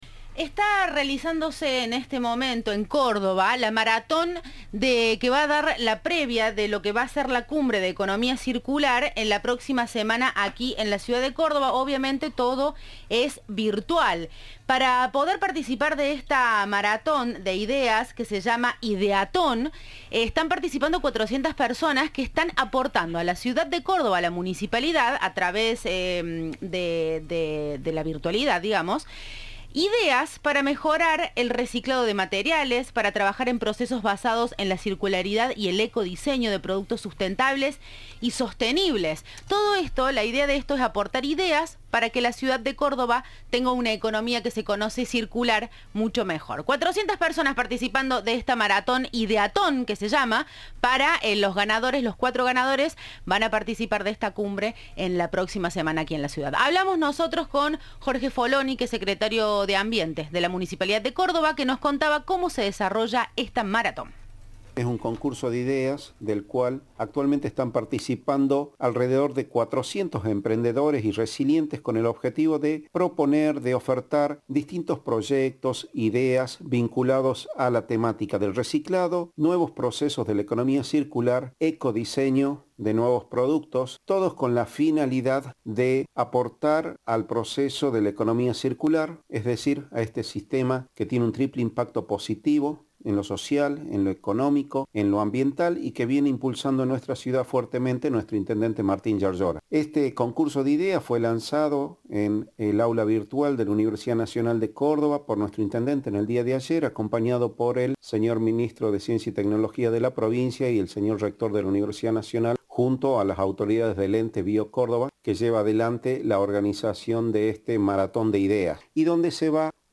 "Es un concurso de ideas del cual participan alrededor de 400 emprendedores con el objetivo de proponer y ofertar distintos proyectos e ideas vinculados al reciclado, nuevos procesos de economía circular y ecodiseño de nuevos productos", dijo a Cadena 3 el secretario de Gestión Ambiental y Sostenibilidad de la Municipalidad de Córdoba, Jorge Folloni.
Entrevista